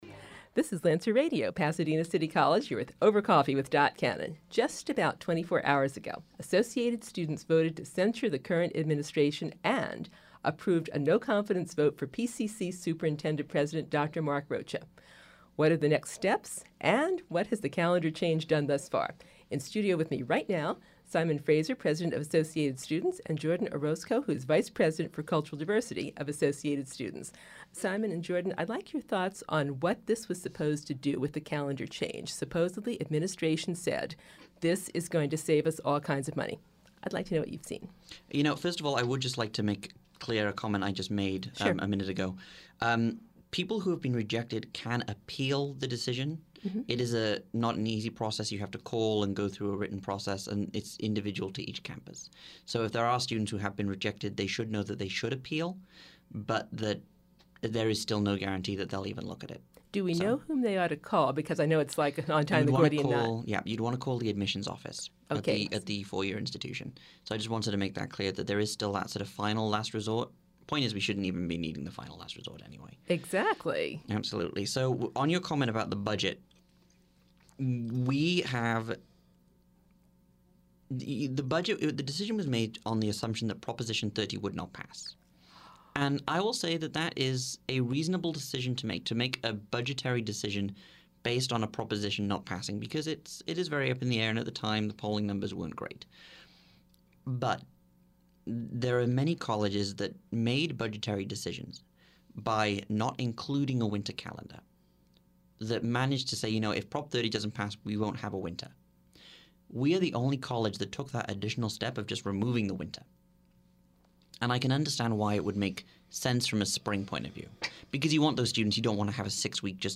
ASPCC Interview, Part Two